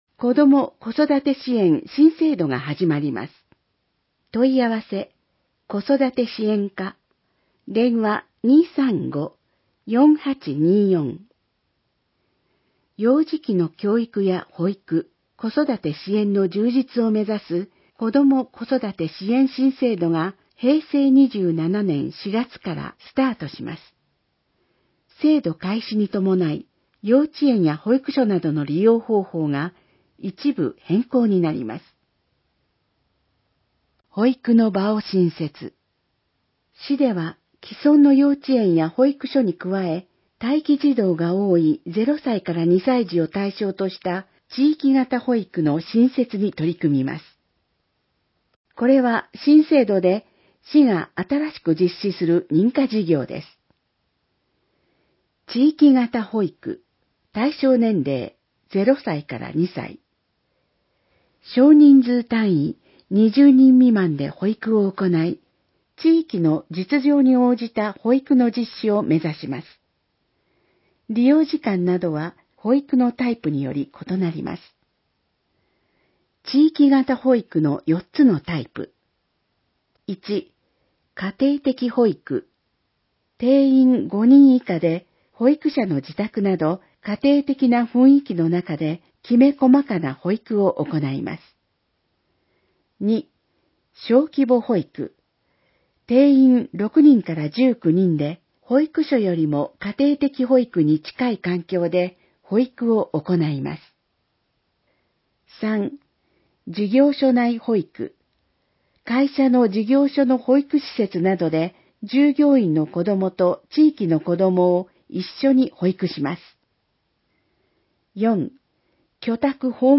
広報えびな 平成26年10月1日号（電子ブック） （外部リンク） PDF・音声版 ※音声版は、音声訳ボランティア「矢ぐるまの会」の協力により、同会が視覚障がい者の方のために作成したものを登載しています。